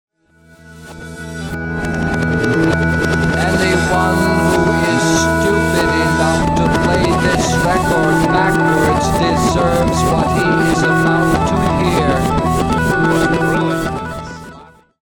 hard rock
Rovesciato